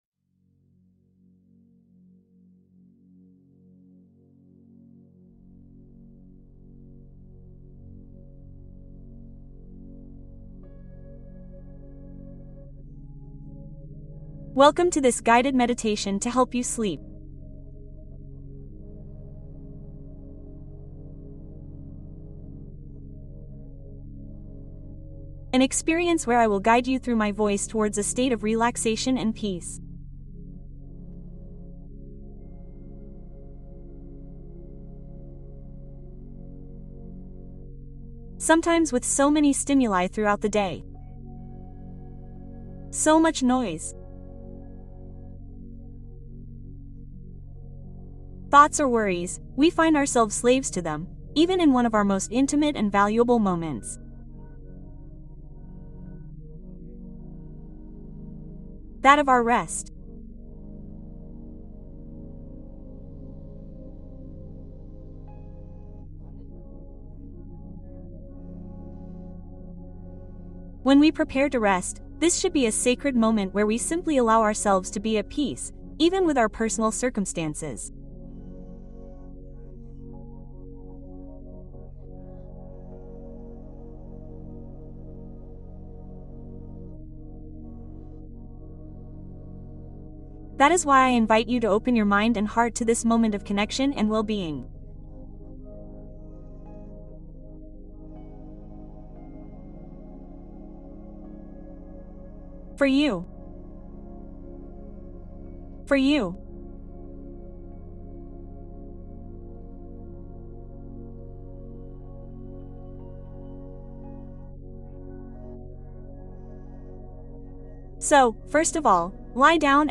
Relajación guiada Adiós ansiedad, duerme con felicidad